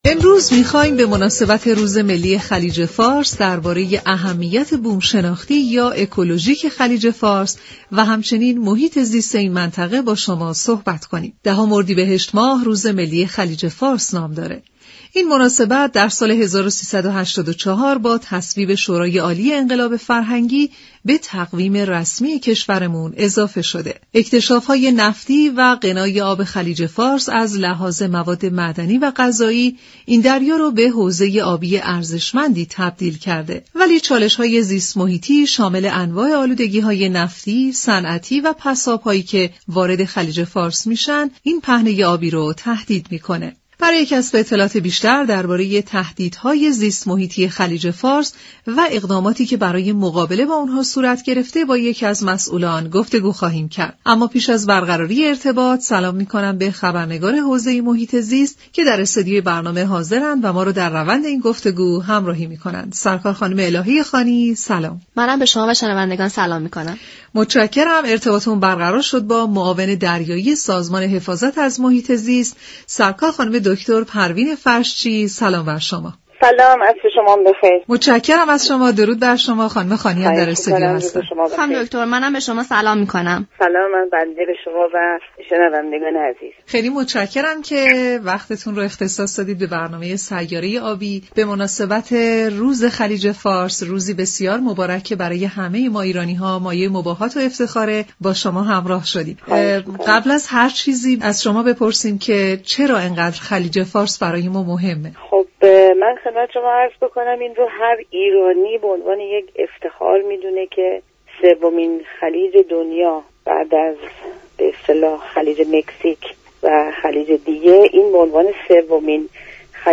معاون محیط زیست دریایی سازمان حفاظت از محیط زیست در گفت و گو با سیاره آبی گفت